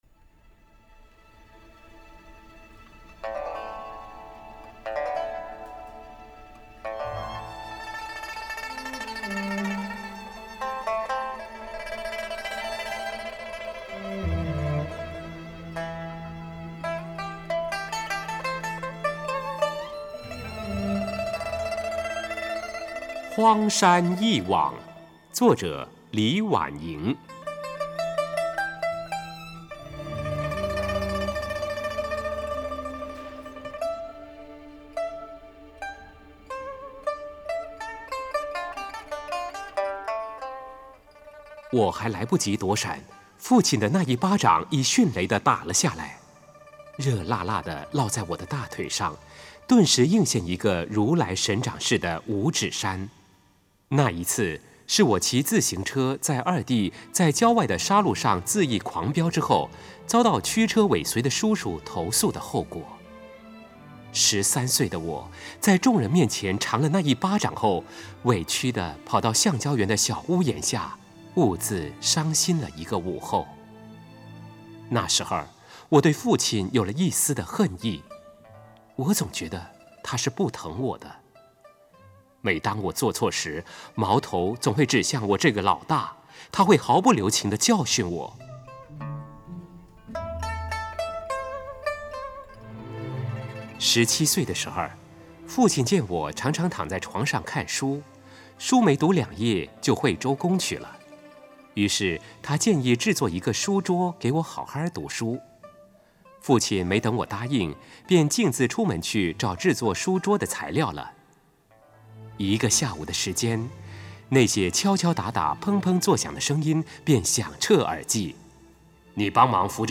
朗读